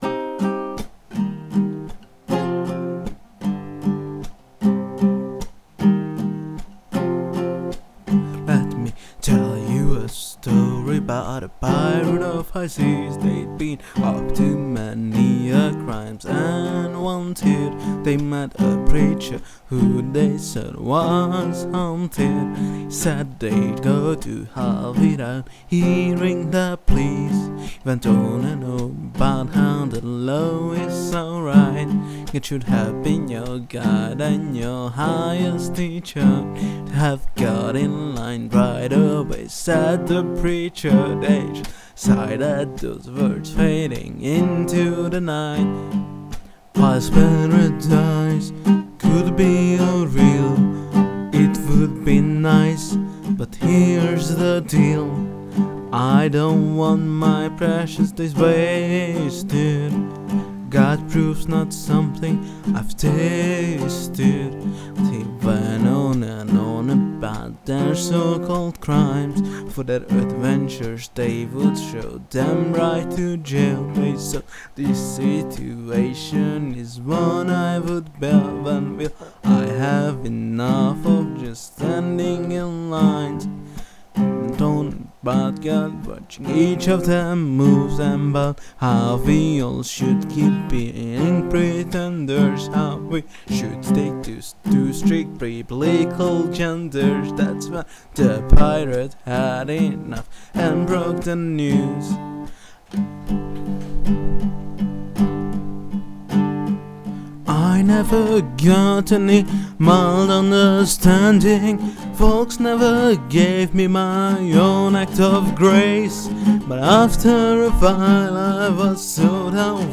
Content warning: oc song (wip) - acoustic